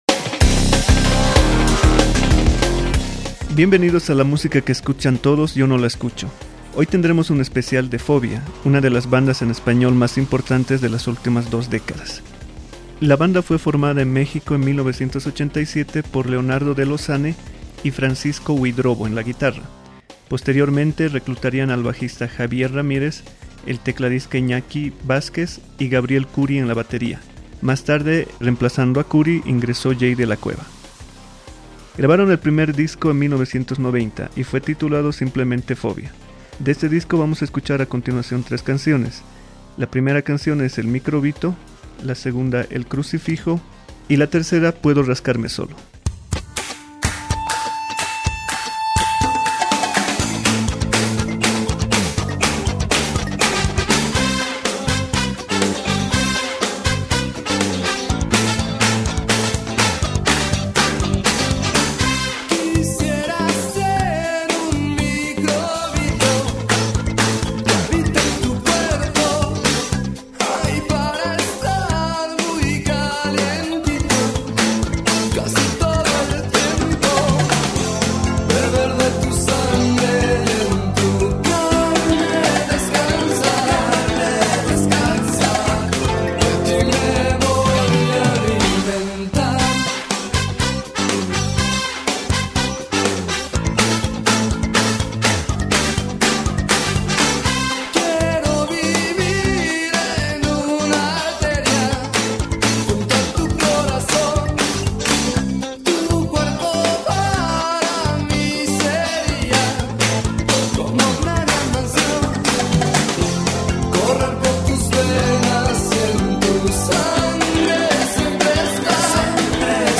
banda de rock mexicano